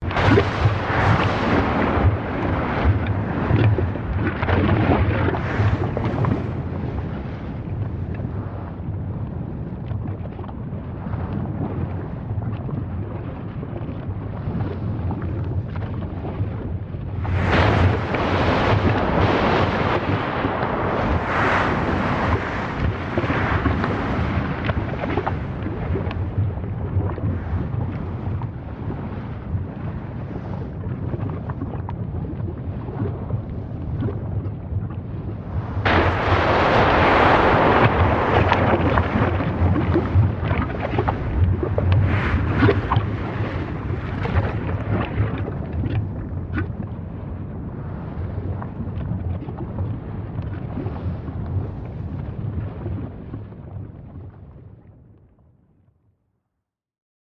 Volcanic Eruptions
SFX
yt_hFUcLDDYUxk_volcanic_eruptions.mp3